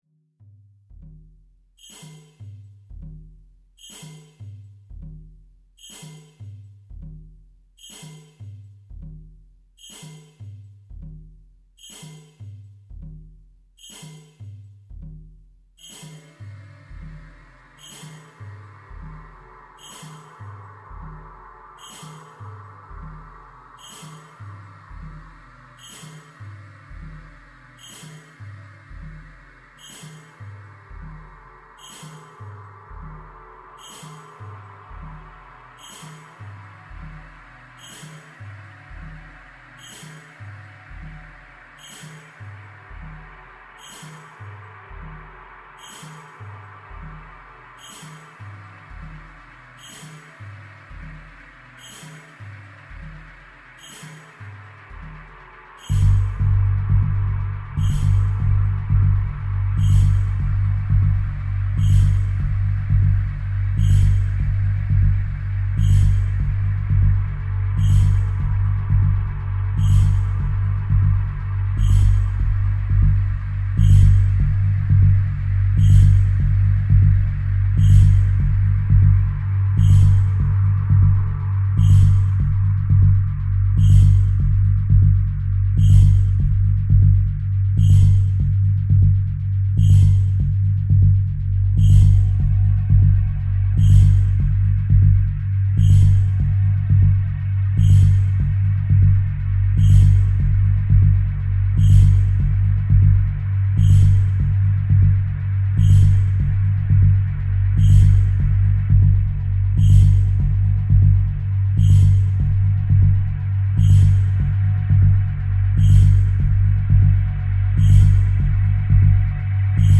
Cinematic Score